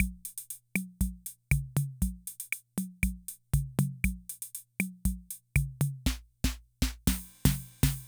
Index of /90_sSampleCDs/300 Drum Machines/Korg Rhythm 55/Korg Rhythm 55 Sample Pack_Audio Files
Korg Rhythm 55 Sample Pack_Loop37.wav